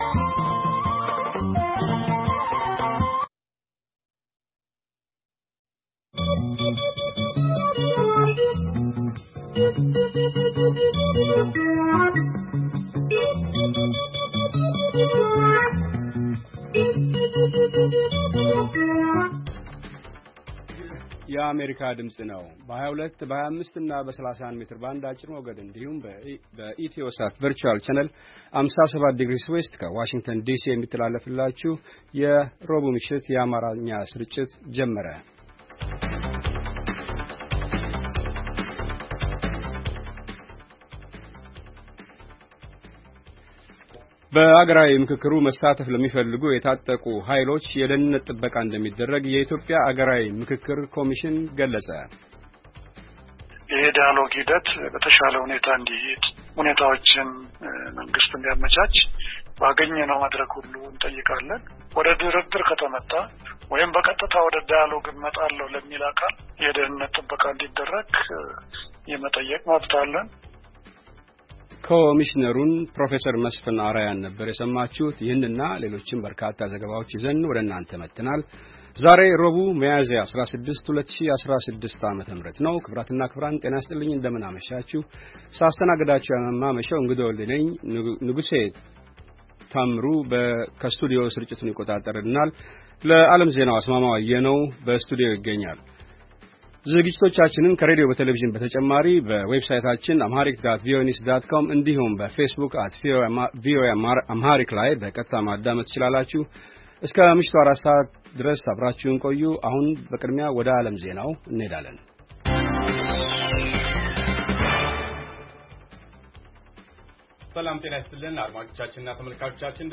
ረቡዕ፡-ከምሽቱ ሦስት ሰዓት የአማርኛ ዜና